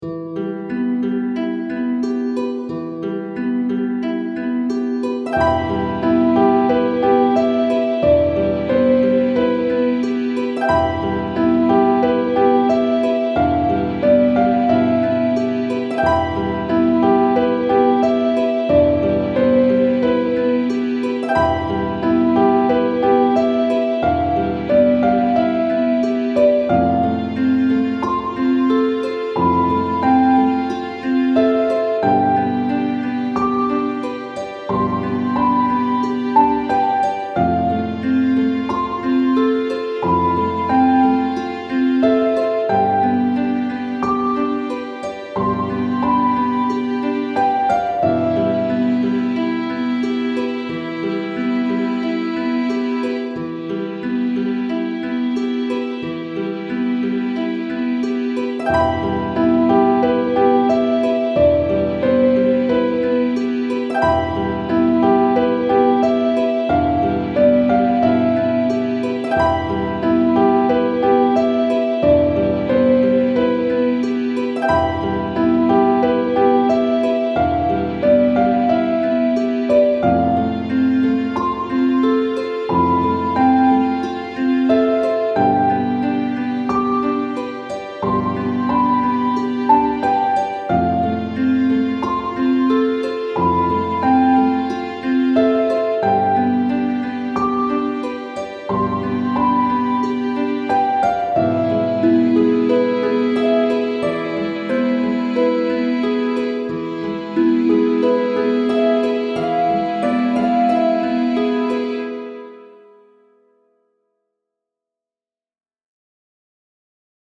ハープとピアノとストリングスな暗い雰囲気のBGMです。